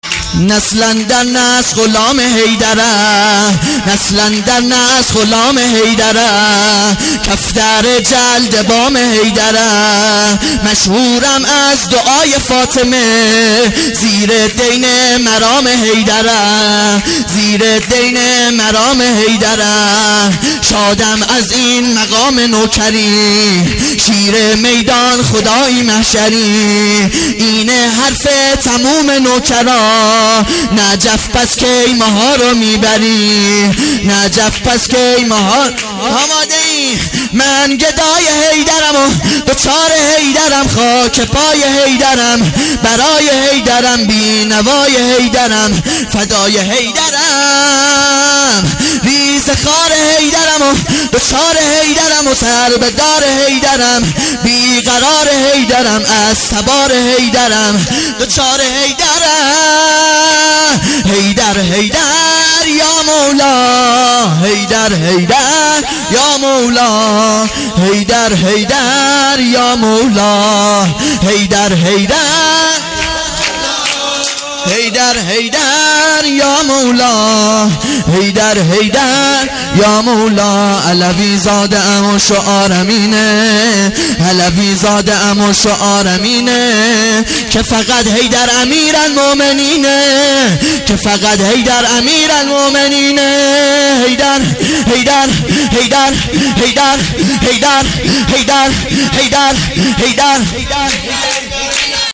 جشن دهه کرامت